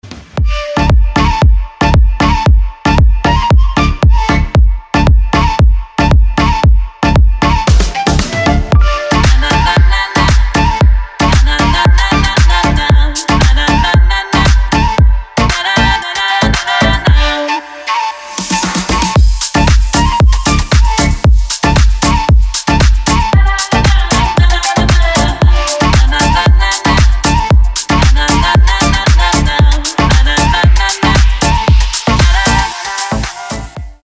deep house
восточные мотивы
женский голос
EDM